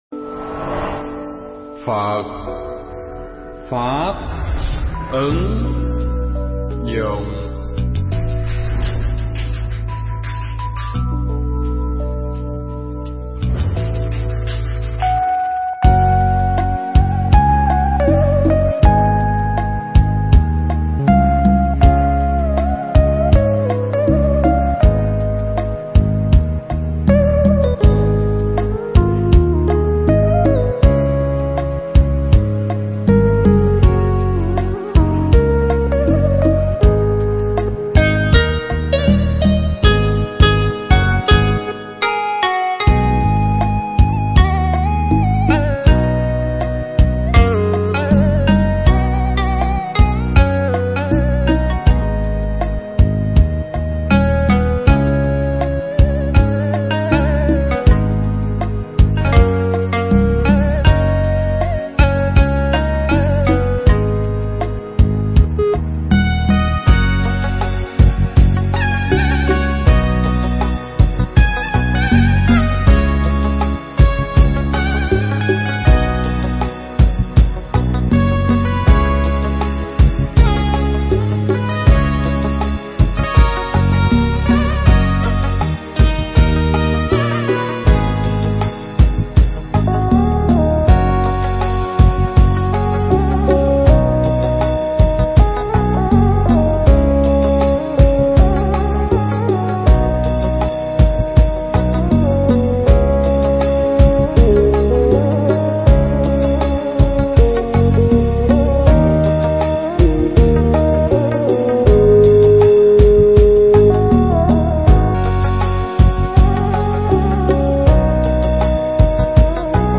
Mp3 Thuyết Giảng Nỗi Lòng Biết Tỏ Cùng Ai
giảng tại chùa Hoa Nghiêm (Bỉ Quốc)